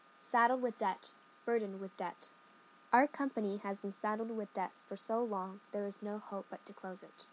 英語ネイティブによる発音は下記のリンクから聞くことができます。